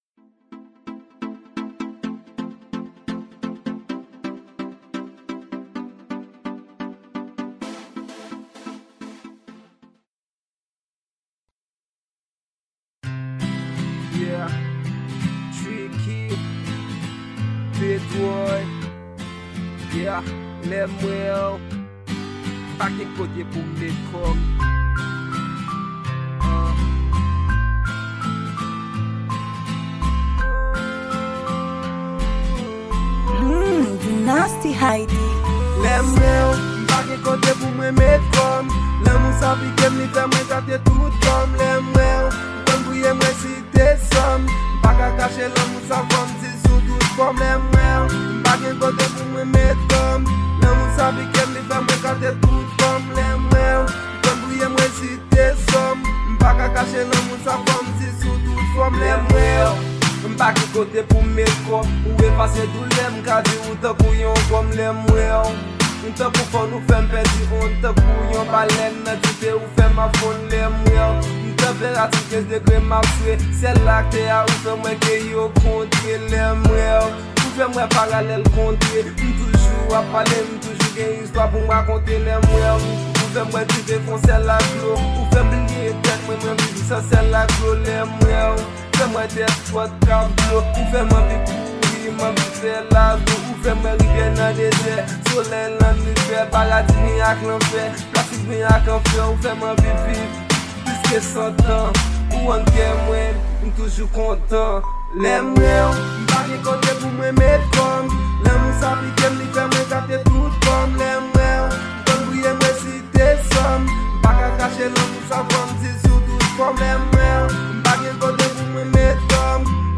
Genre: Rap & Rnb.